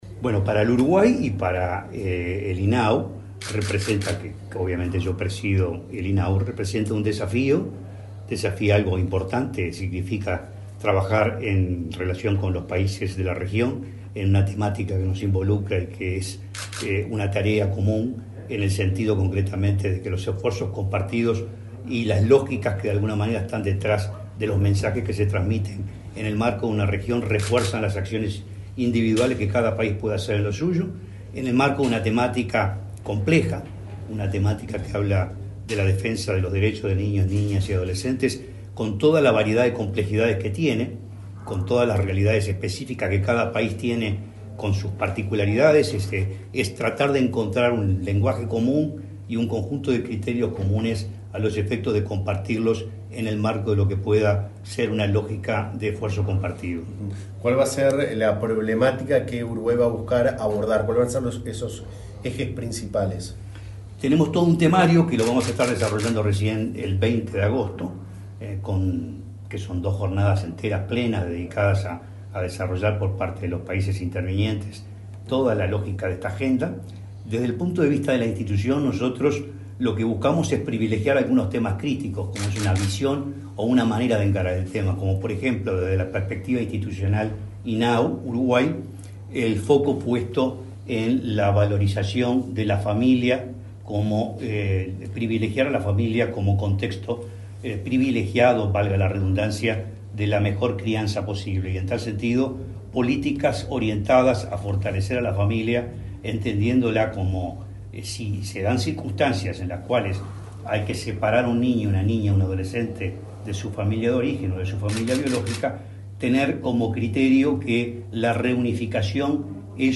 Declaraciones del presidente del INAU, Guillermo Fosatti
El presidente del Instituto del Niño y el Adolescente del Uruguay (INAU), Guillermo Fosatti, dialogó con la prensa, antes de participar en el acto en